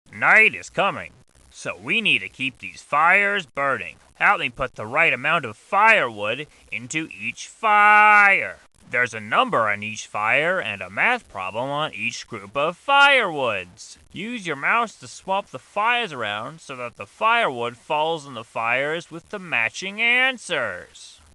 Голос Балди и звуковые эффекты из игры для монтажа видео в mp3 формате